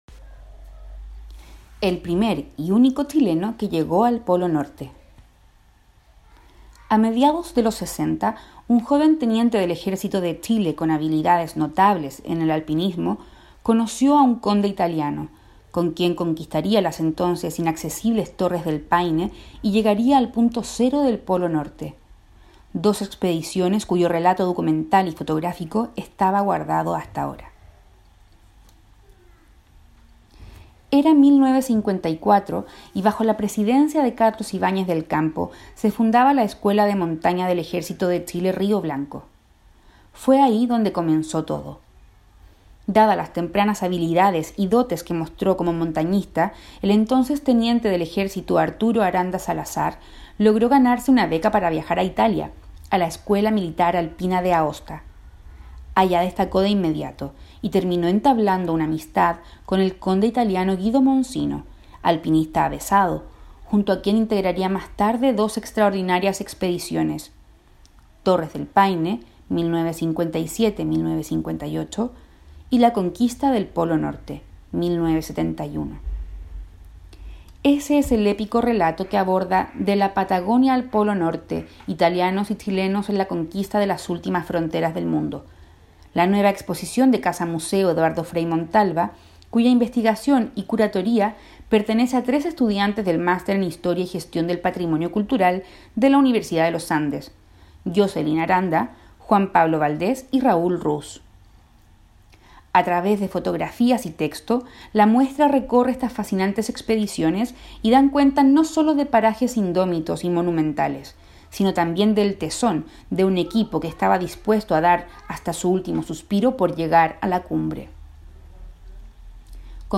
Audio artículo